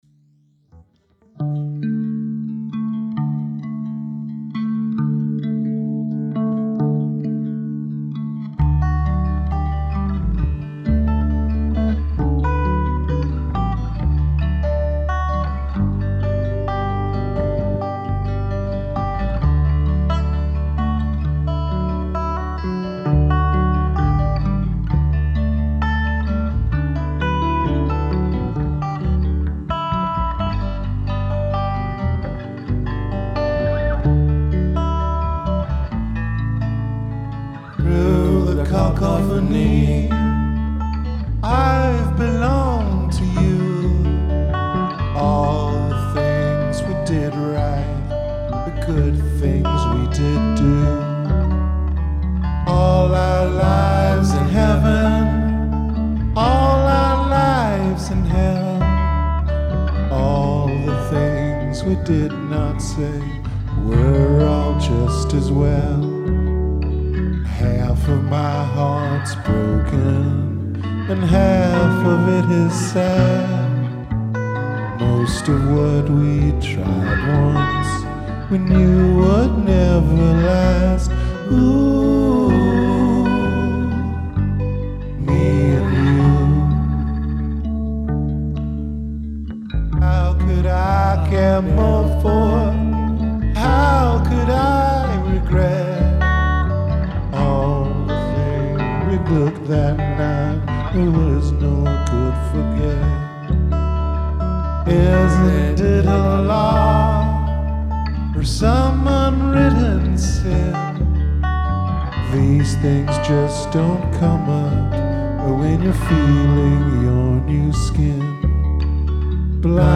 Category: Rehearsal recordings Date: January 11, 2012 9.2 MiB 15 Downloads Details…